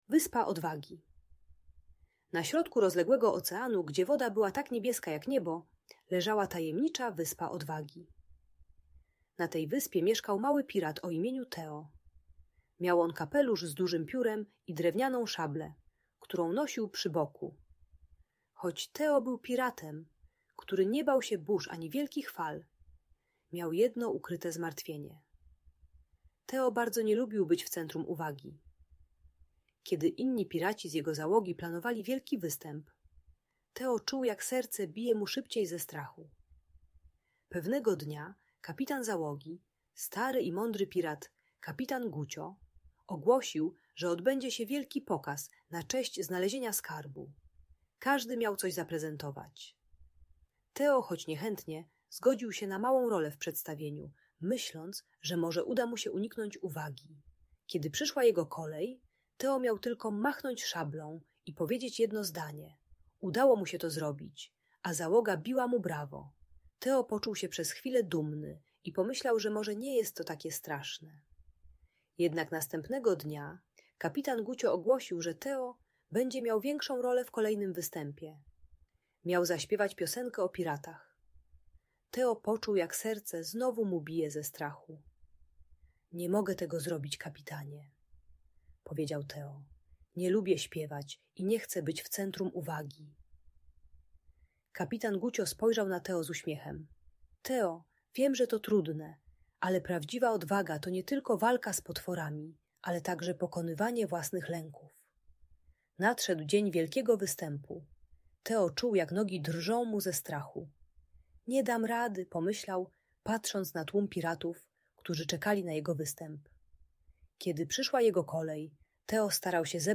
Piracka opowieść o odwadze - Lęk wycofanie | Audiobajka